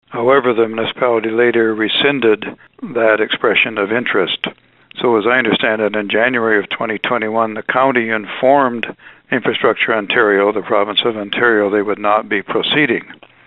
Toby Barrett acknowledged the upcoming sale of the Normandale beach in a phone call on Wednesday.